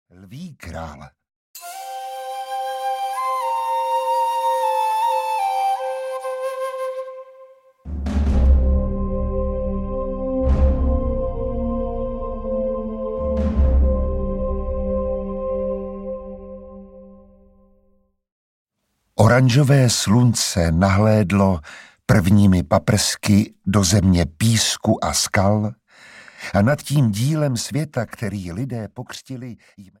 Disney - Lví král audiokniha
Ukázka z knihy